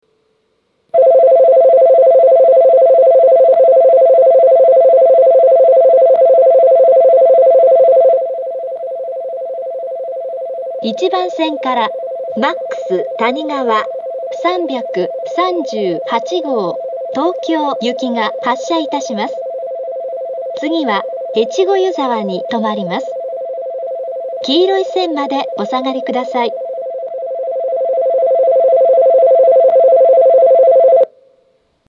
１番線発車ベル Ｍａｘたにがわ３３８号東京行の放送です。